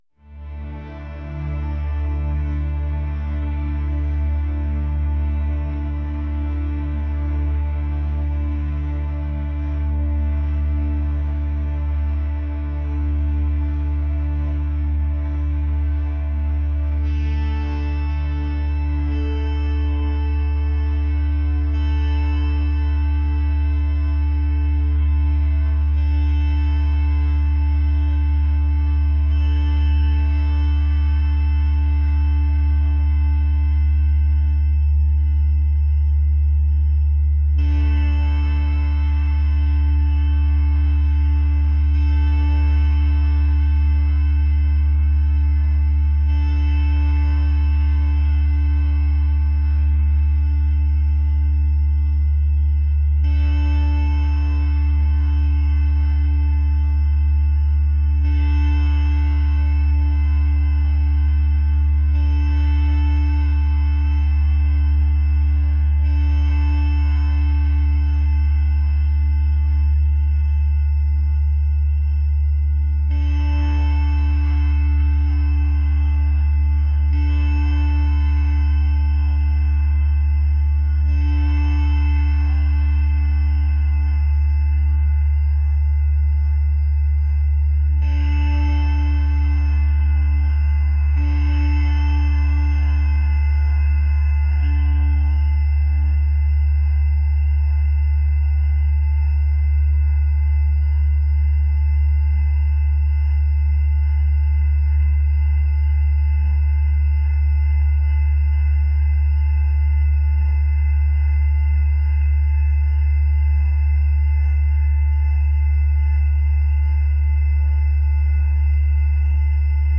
rock | atmospheric